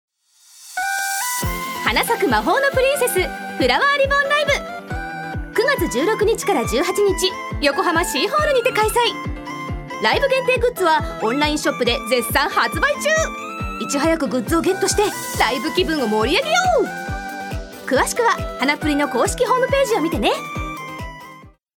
女性タレント
ナレーション３